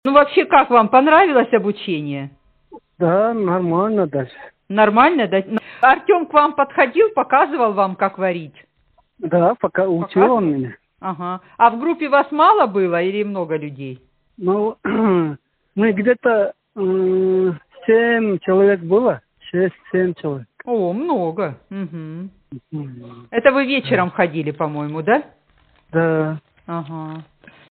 Аудио Отзывы